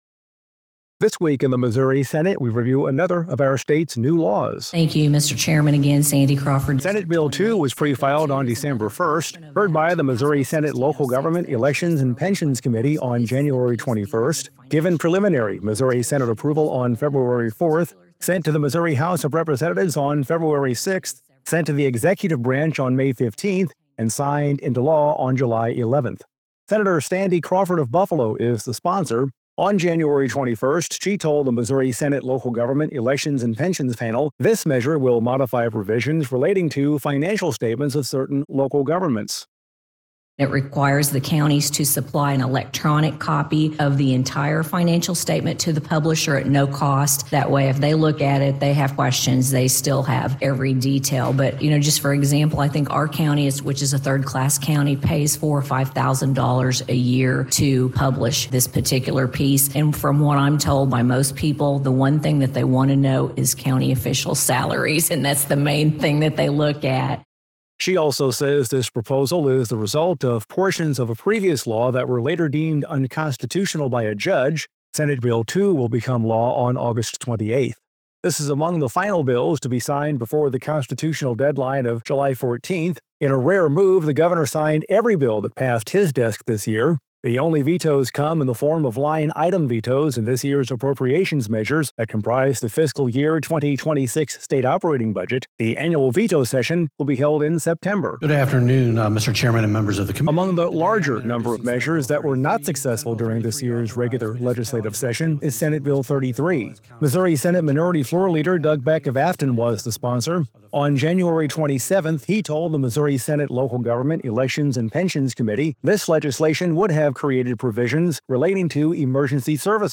Every Friday, Senate Communications offers This Week in the Missouri Senate, a wrap up of the week’s actions that includes audio from floor debate, committee hearings and one-on-one interviews with the senators themselves.